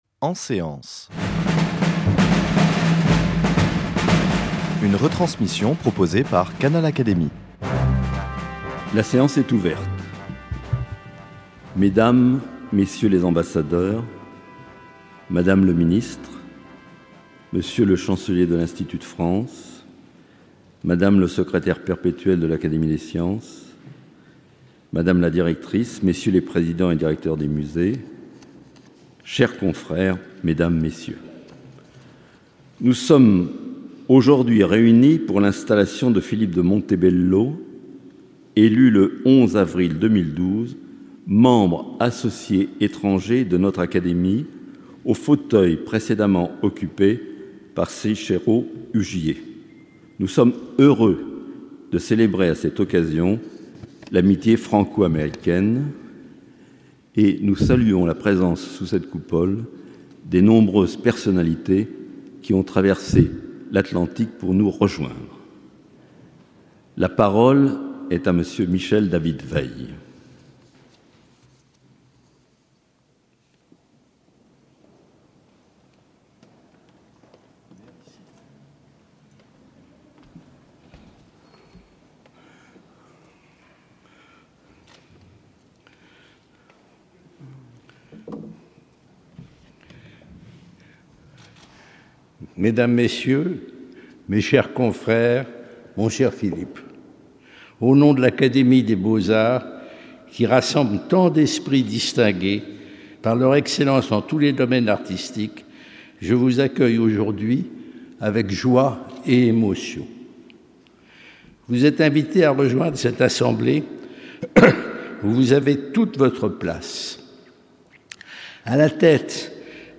Au cours de cette cérémonie sous la Coupole de l’Institut de France, Michel David-Weill, membre de la section des Membres libres de l’Académie, a prononcé le discours d’installation de Philippe de Montebello avant d’inviter ce dernier à faire, selon l’usage, l’éloge de son prédécesseur.